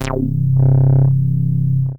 gate_warp.wav